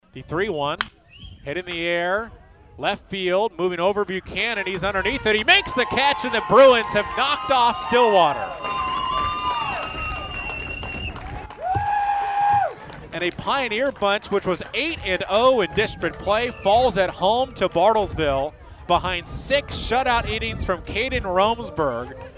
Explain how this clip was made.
Here was the final call from Couch Park in Stillwater.